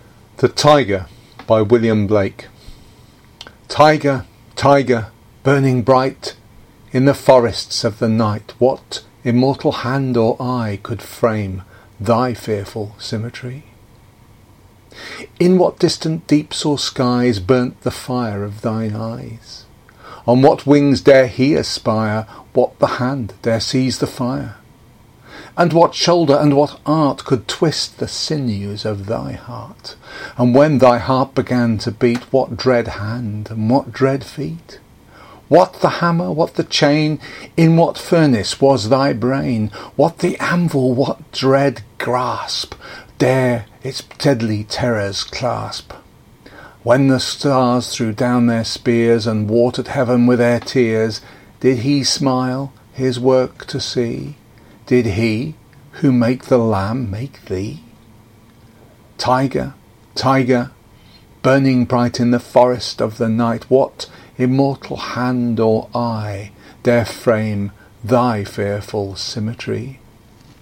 Here are some examples of me reading verse and pros by others:-